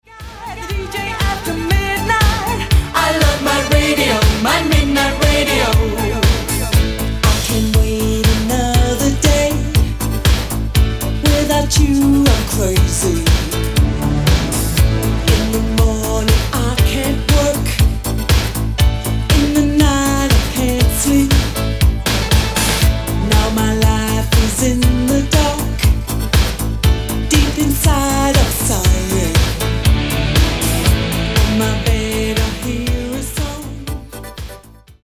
Genere: Euro Disco